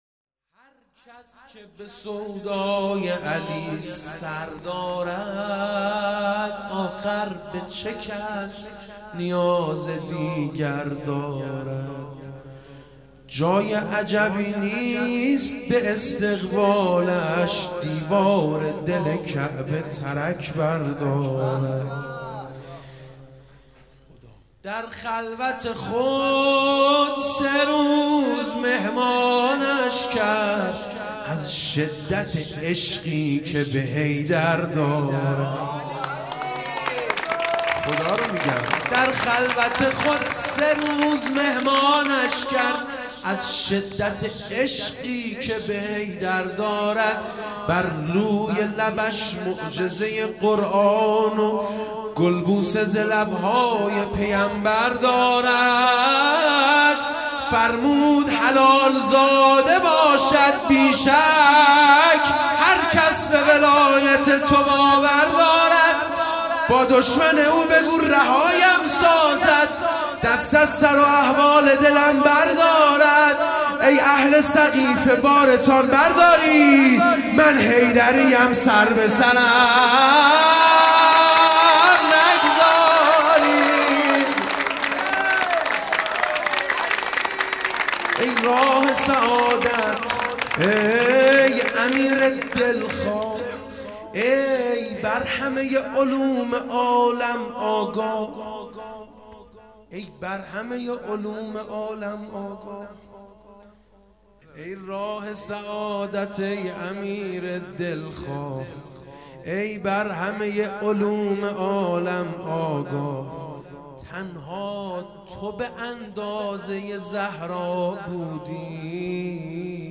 میلاد امام حسن عسکری(ع) - سه شنبه١٢آذرماه١٣٩٨